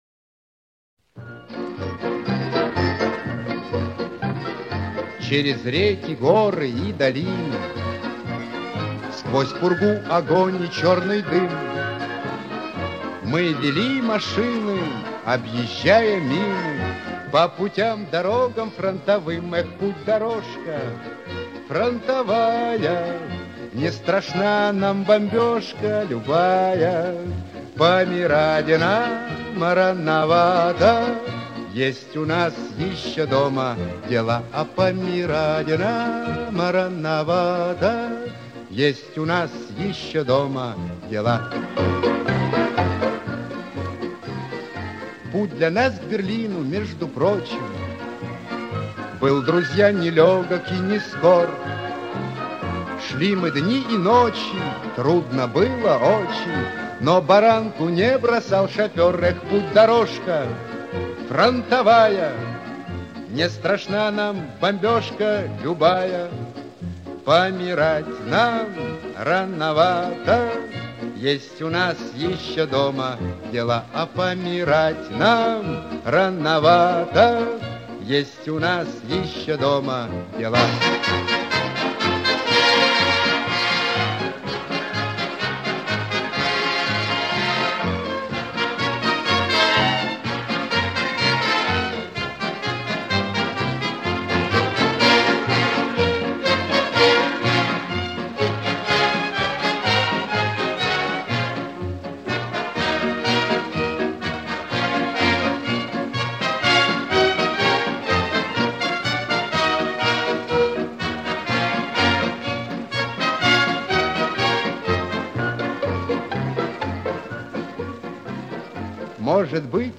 Более раннее исполнение